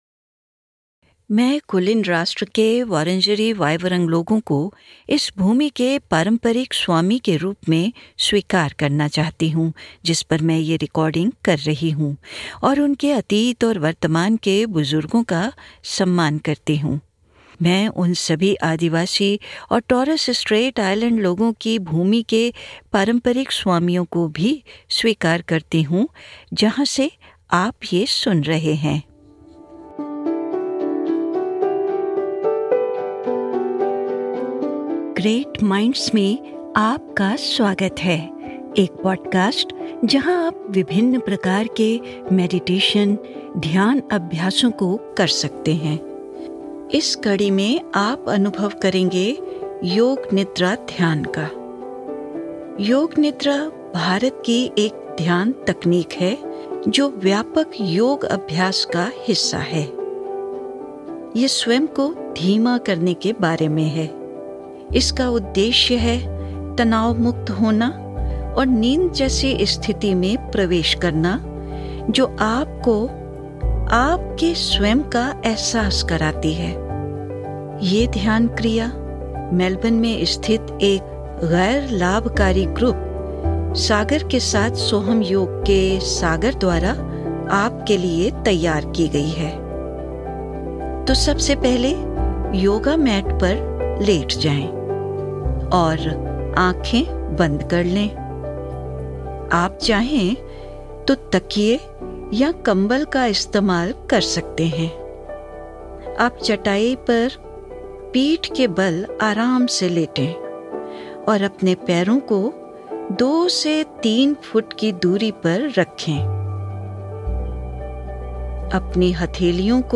इस कड़ी में, भारत में उत्पन्न हुई एक प्राचीन ध्यान तकनीक, योग निद्रा के साथ गहरी शांति का अनुभव करें।